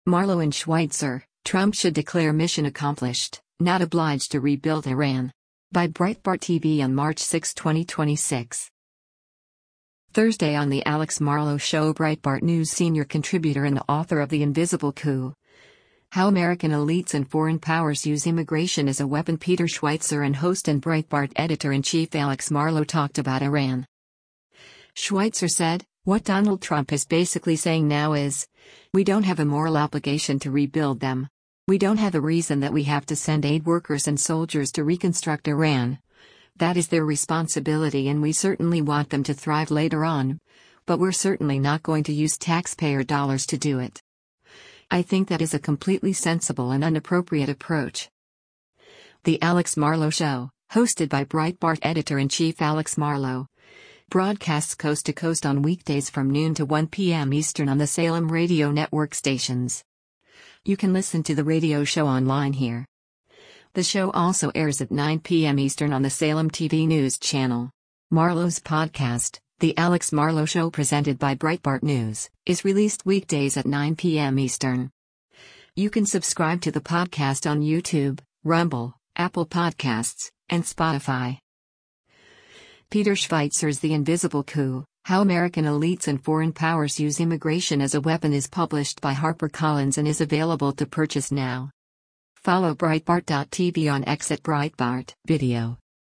Thursday on “The Alex Marlow Show” Breitbart News senior contributor and author of The Invisible Coup: How American Elites and Foreign Powers Use Immigration as a Weapon Peter Schweizer and host and Breitbart Editor-in-Chief Alex Marlow talked about Iran.